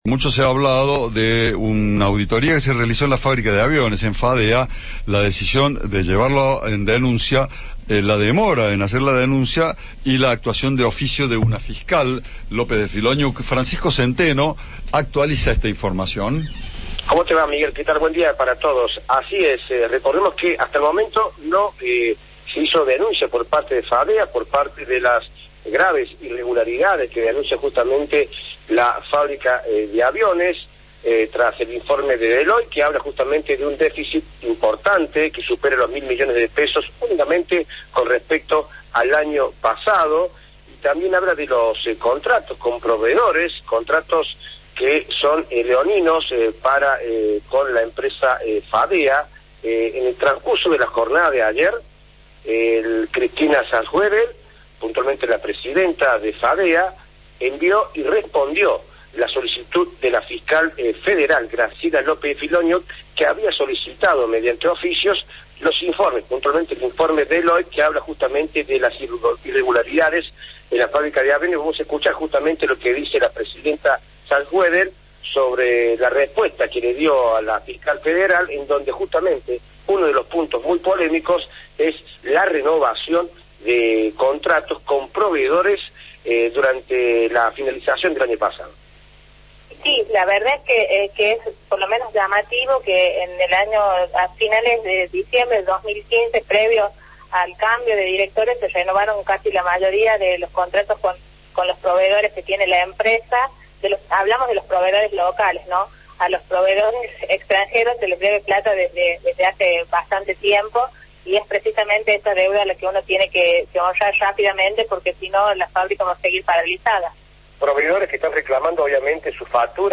La titular de Fadea dijo a Cadena 3 que hay deudas millonarias.